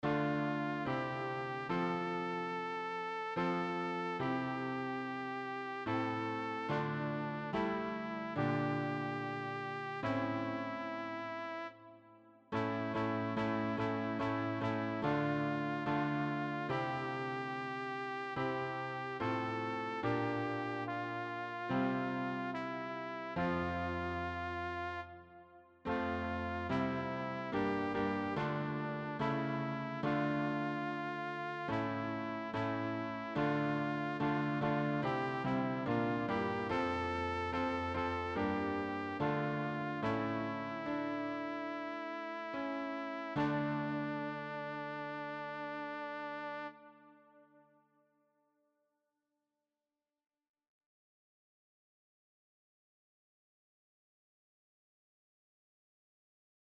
sopran-1. Aller Augen warten auf dich, Herre.mp3